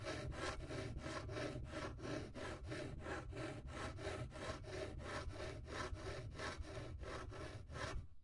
st saw plastic surface
描述：sawing a plastic surface
标签： plastic saw surface
声道立体声